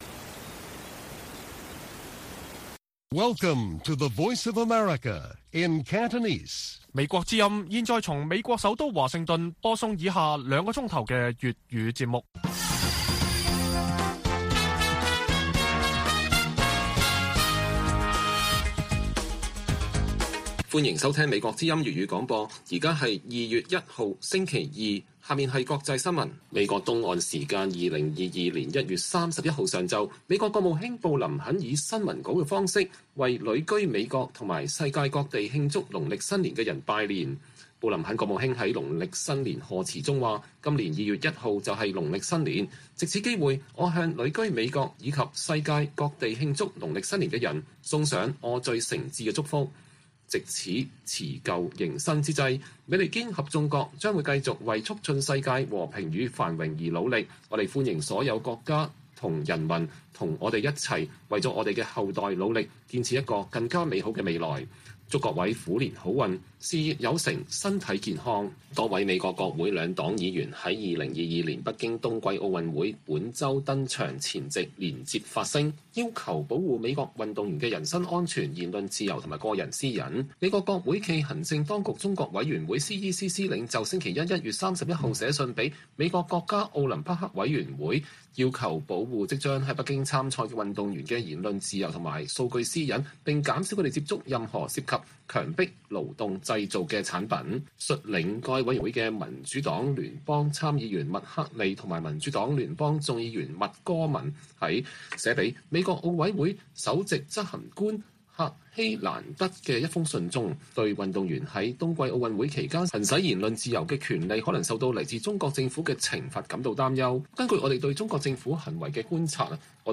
粵語新聞 晚上9-10點: 布林肯國務卿發表農曆虎年賀詞